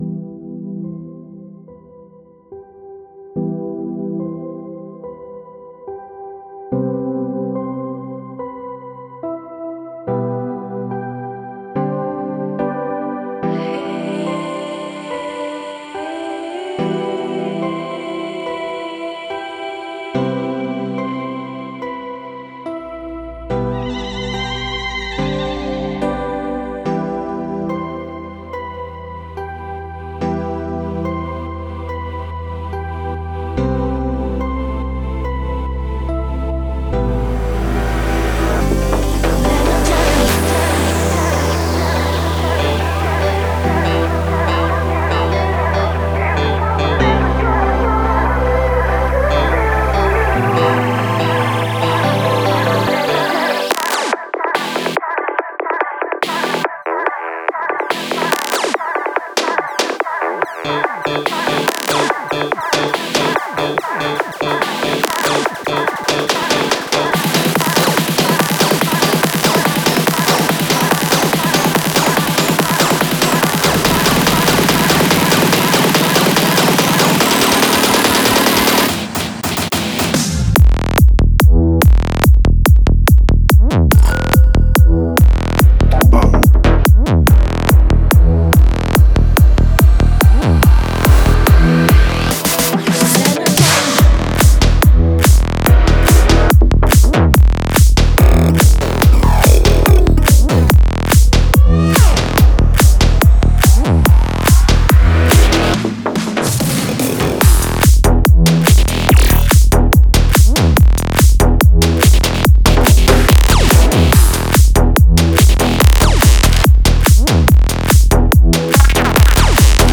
Файл в обменнике2 Myзыкa->Psy-trance, Full-on
Стиль: Psy Trance